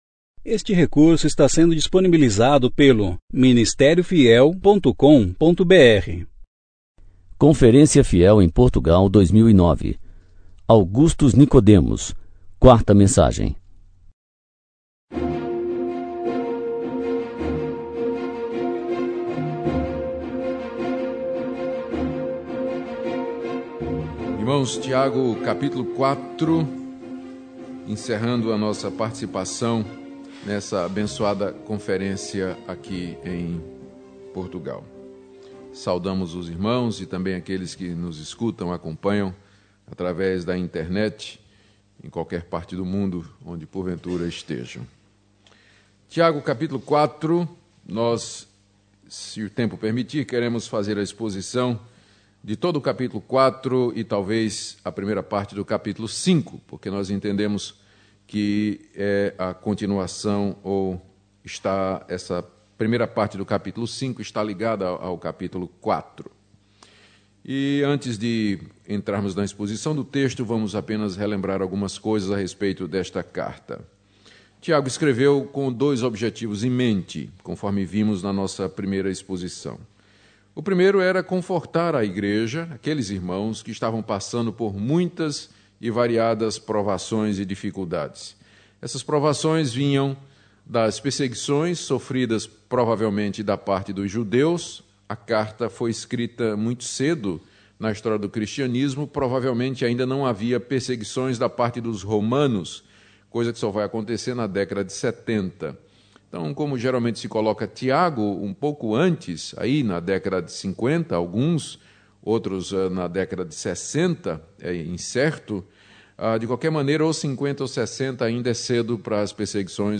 Conferência: 9ª Conferência Fiel – Portugal Tema: A Palavra e a Pregação Ano